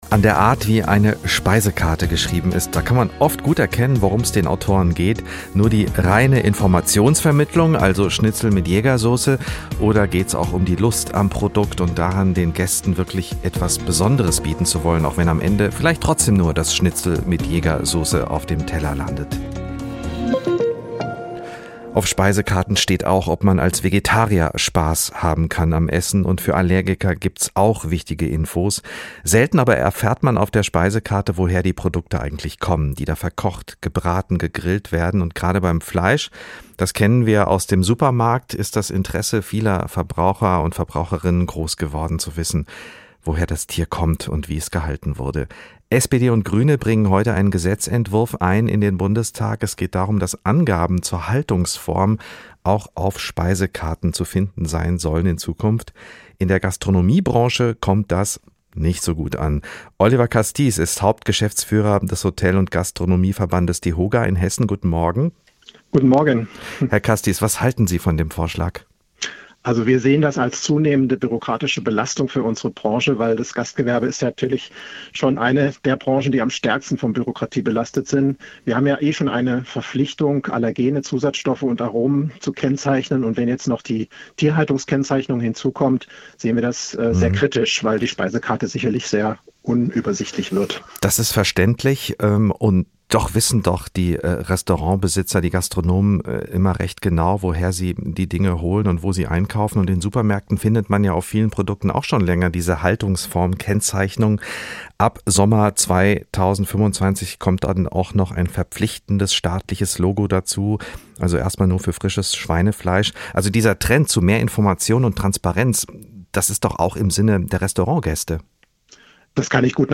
In der Gastronomiebranche kommt dieser Vorschlag nicht gut an. hr INFO Moderator